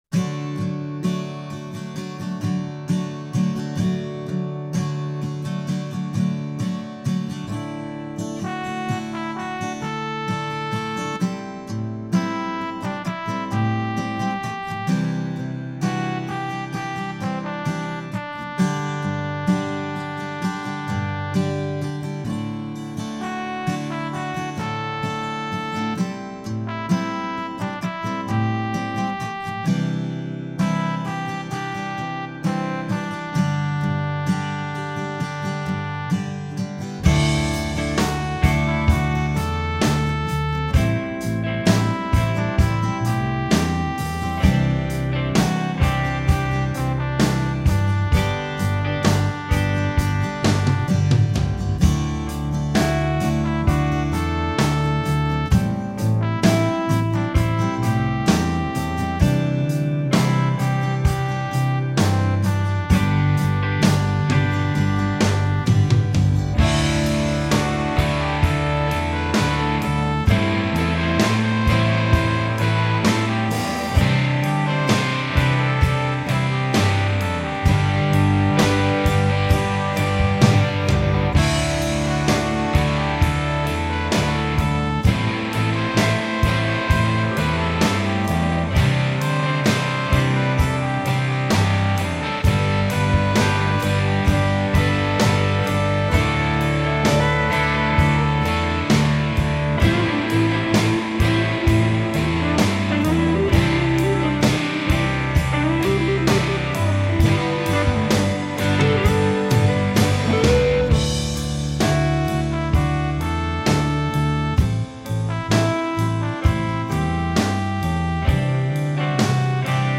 My backing is probably a little approximate.